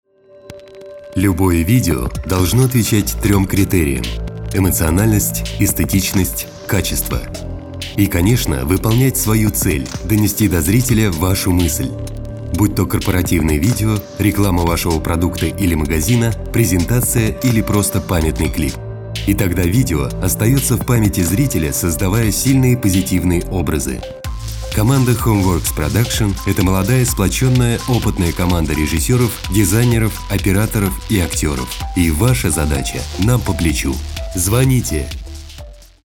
HOME WORKS PRODUCTION. Начитка для видеоролика.